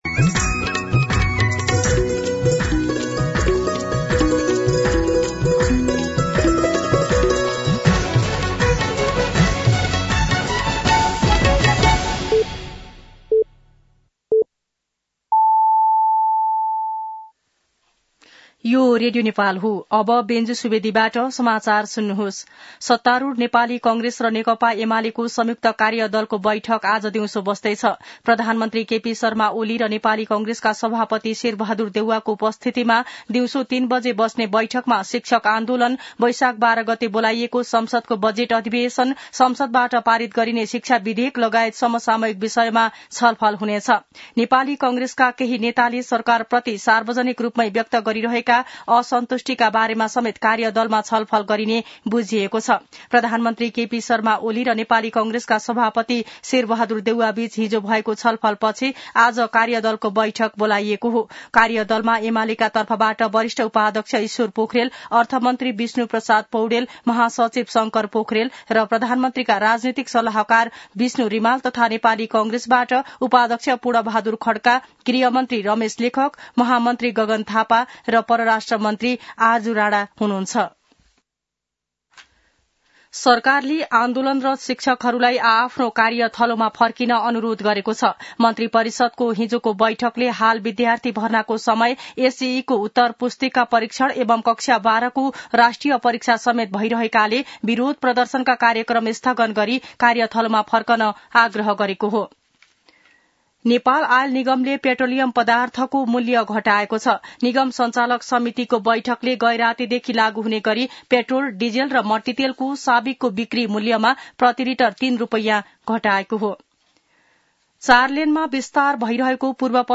मध्यान्ह १२ बजेको नेपाली समाचार : ३ वैशाख , २०८२
12-pm-news-.mp3